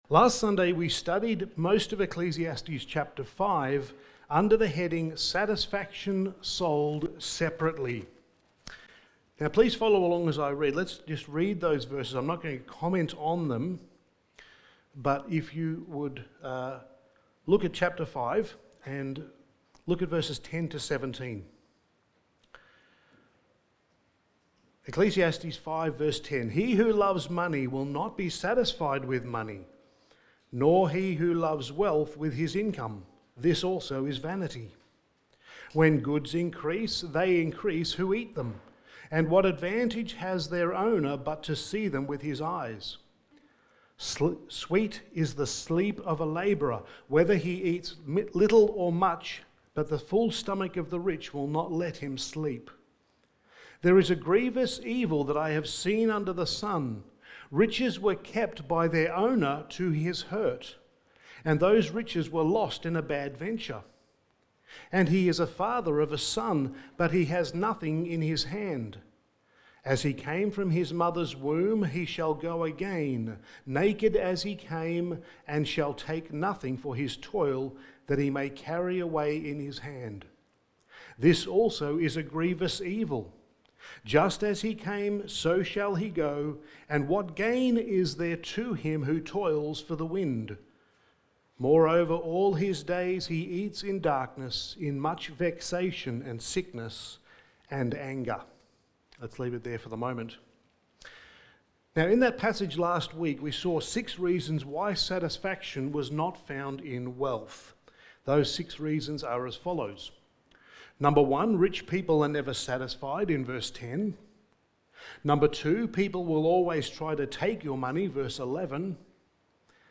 Studies in the Book of Ecclesiastes Sermon 11: Here Today, Gone Tomorrow
Service Type: Sunday Morning